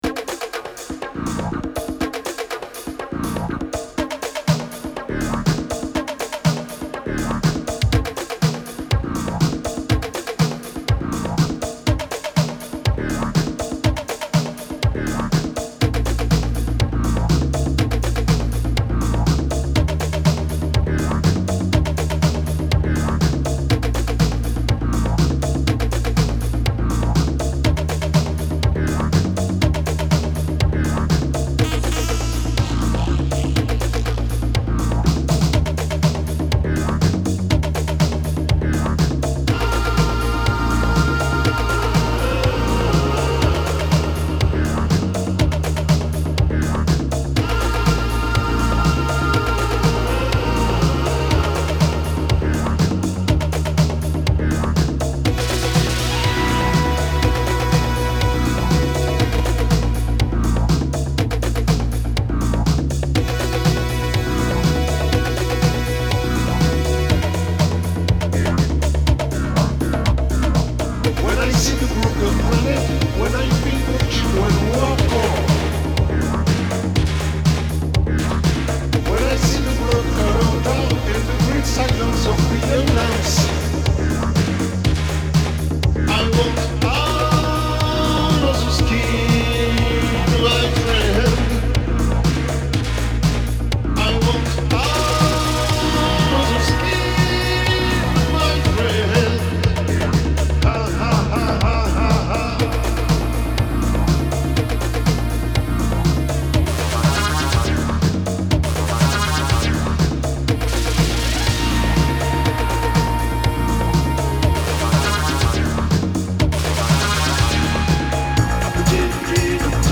Filed under coldwave, disco, electronic